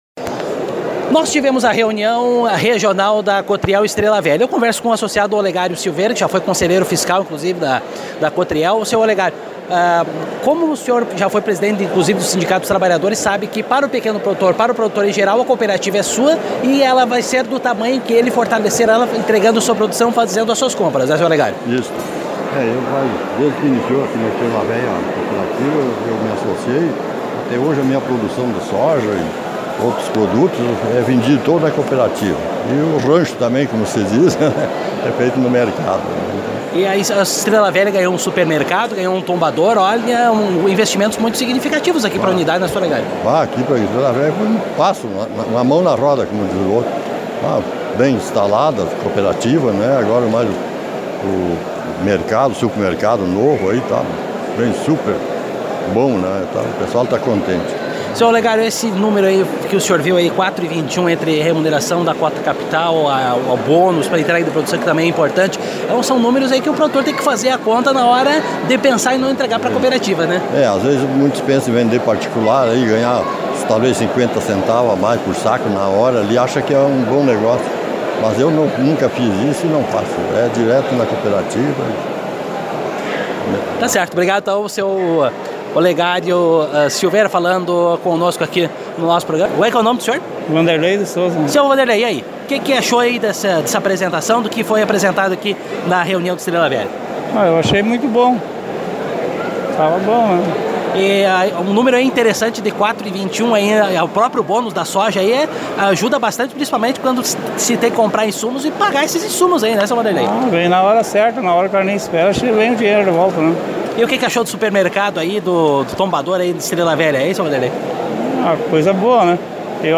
Durante as reuniões regionais, realizadas de 28 de fevereiro a 09 de março em todas as Unidades da Cooperativa, ouvimos associados e diretores da Cotriel sobre os números apresentados pela Cooperativa.
aqui as entrevistas.